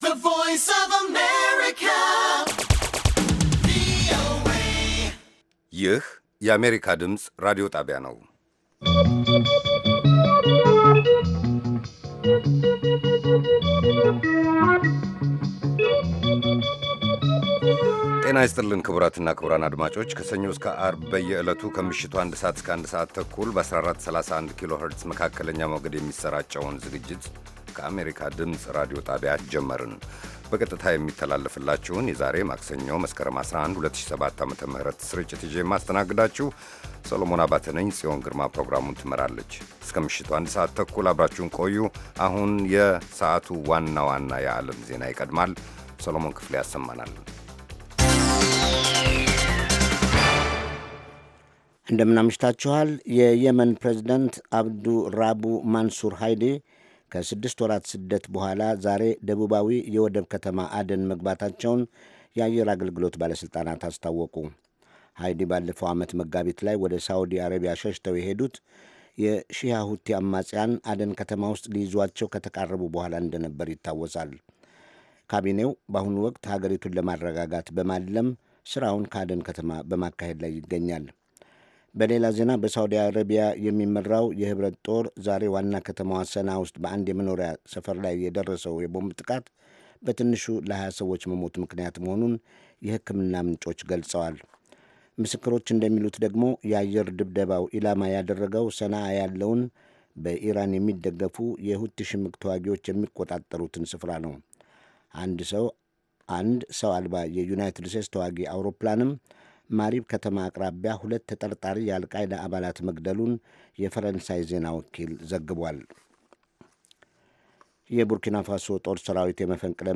ከምሽቱ አንድ ሰዓት የአማርኛ ዜና